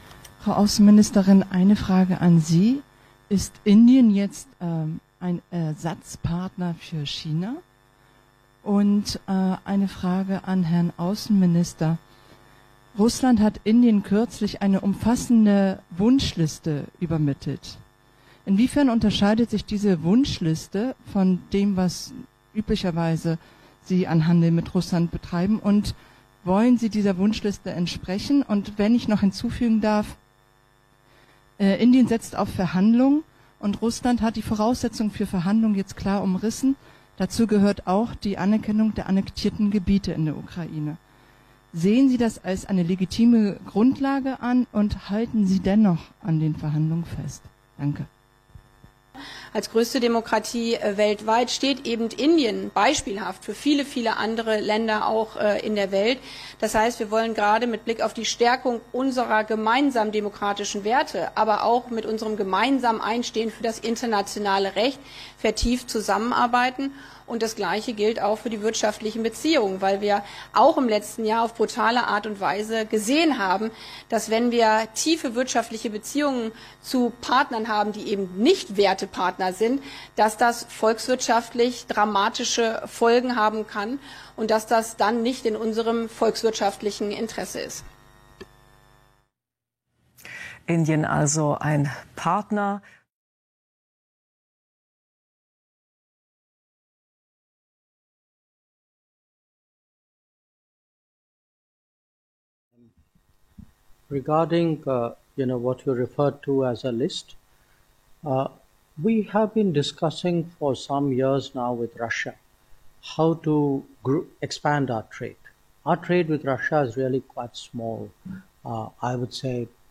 Der Sender Phoenix überträgt die Pressekonferenz von Baerbock und ihrem indischen Amtskollegen in Neu-Delhi. Die Sendung wird jedoch an der interessantesten Stelle unterbrochen.